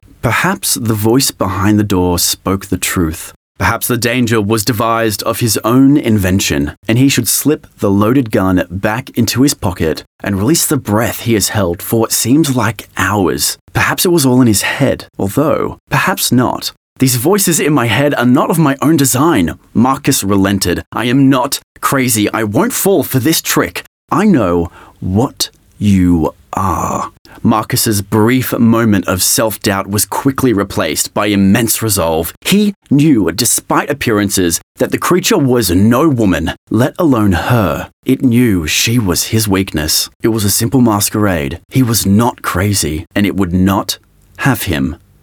Audio Book Voice Over Narrators
Yng Adult (18-29)